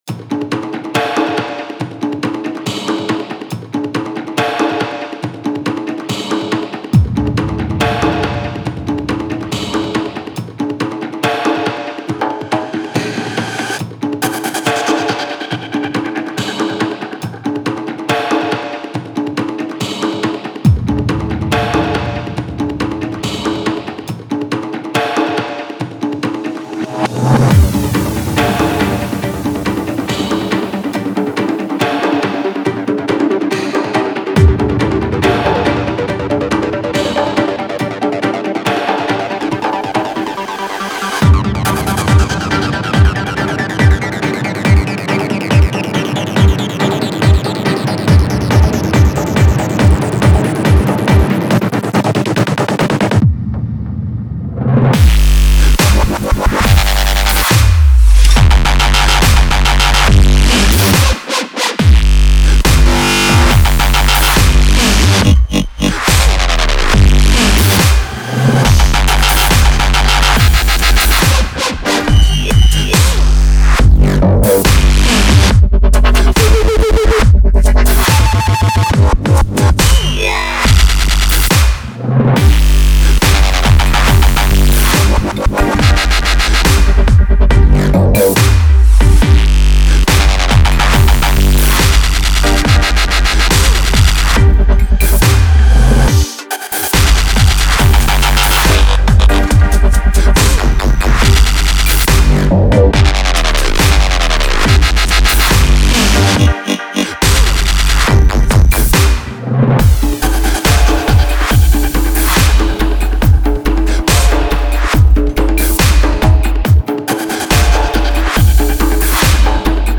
Категория: Dub step